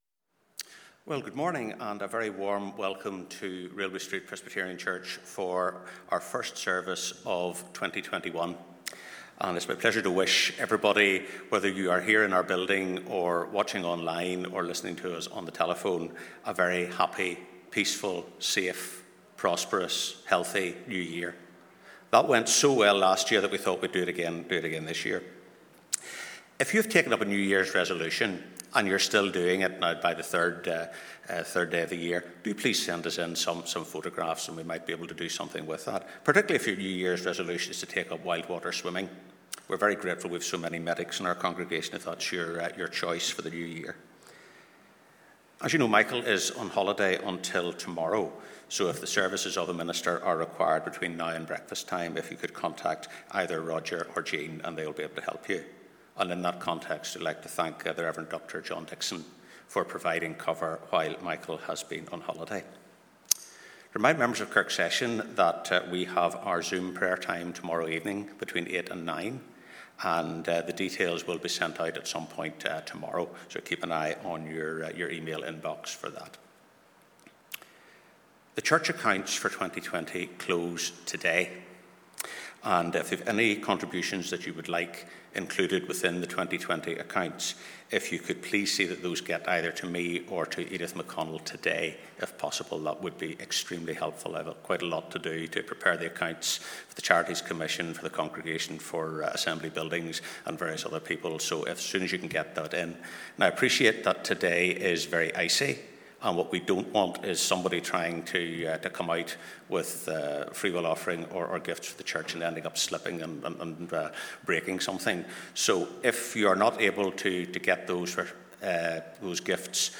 The Right Rev. Dr David Bruce leads us in a pre-recorded service based on the “Immanuel” prophecy in Isaiah 7 and Matthew 1, while looking forward into the new year.
Sunday 3rd January 2021 Live @ 10:30am Morning Service Right Rev. Dr David Bruce Isaiah 7 & Matthew 1 Audio will be available after the service.